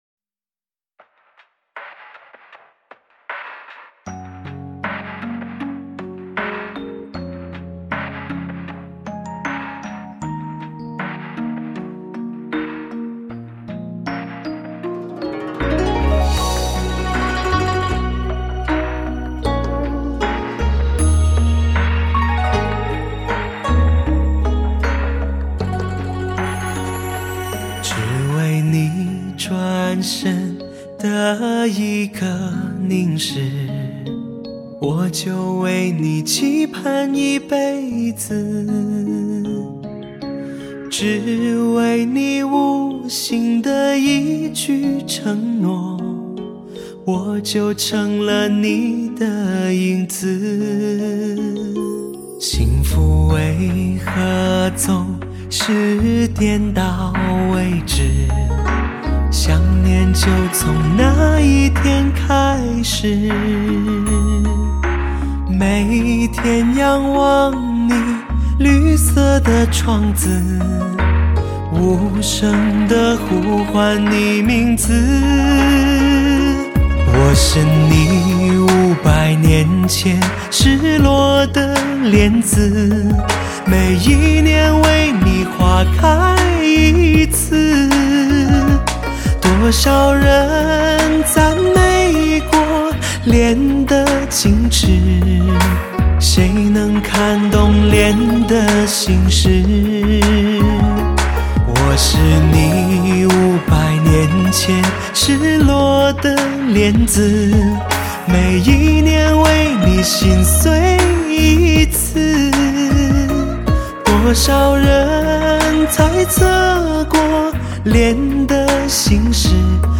引领潮流发烧试音典范
绝佳的录音技术不容置疑 极具声色感染力的发烧音效